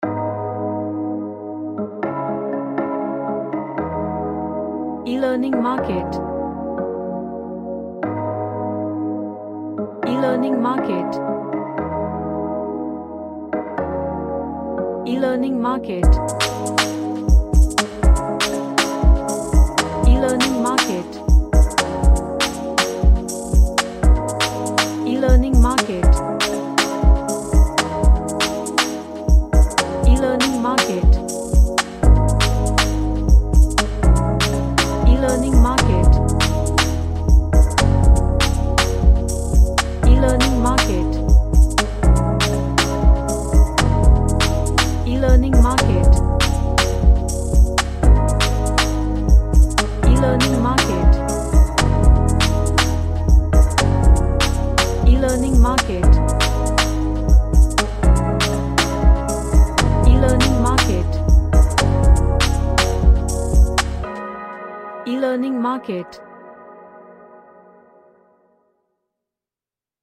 A chill track with cyber pop vibe
Aerobics / Workout